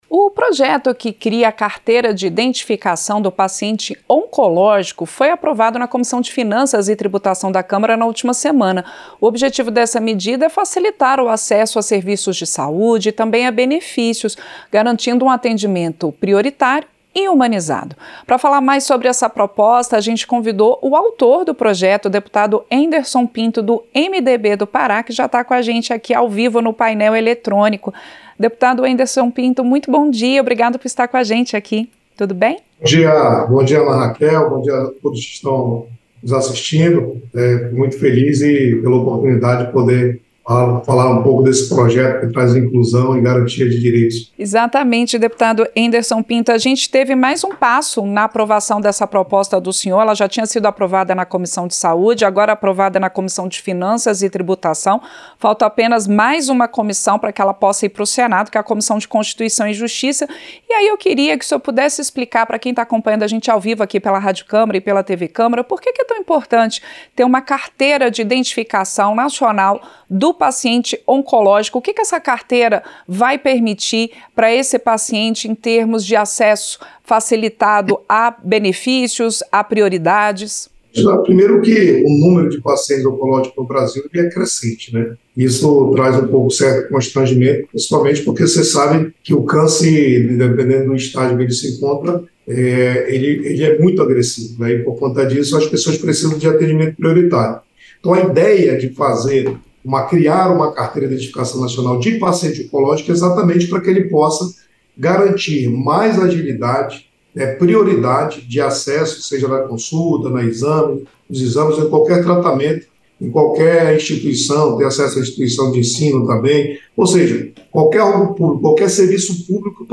• Entrevista - Dep. Henderson Pinto (MDB-PA)
Programa ao vivo com reportagens, entrevistas sobre temas relacionados à Câmara dos Deputados, e o que vai ser destaque durante a semana.